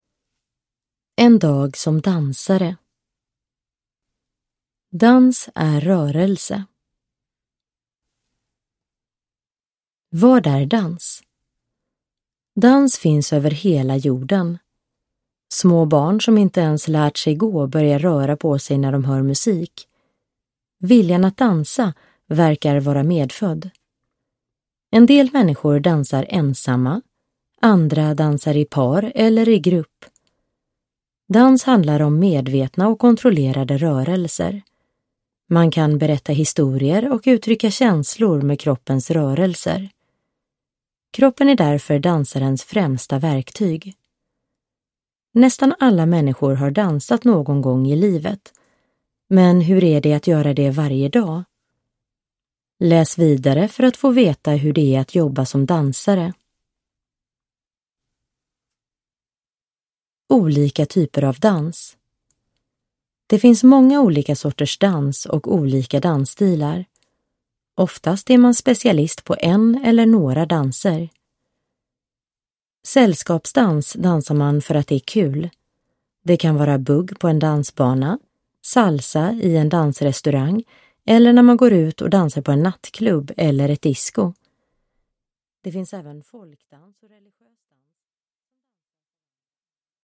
En dag som dansare – Ljudbok – Laddas ner